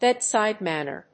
アクセントbédside mánner
音節bèdside mánner